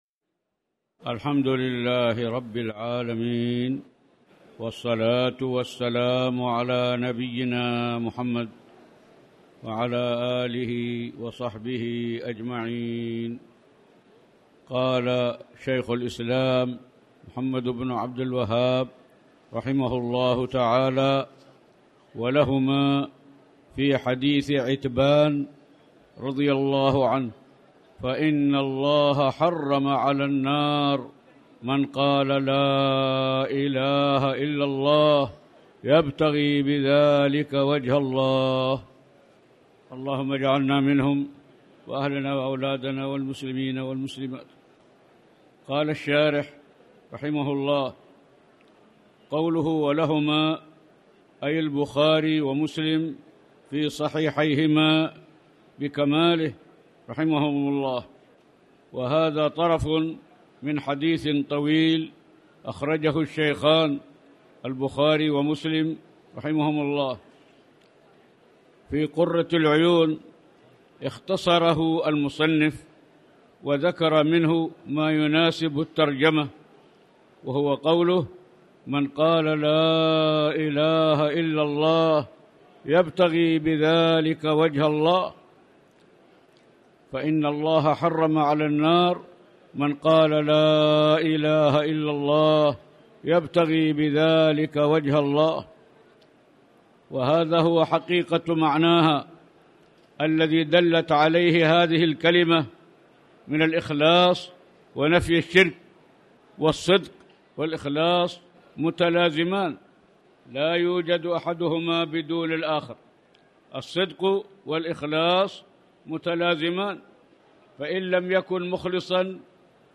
تاريخ النشر ٢٧ ذو القعدة ١٤٣٨ هـ المكان: المسجد الحرام الشيخ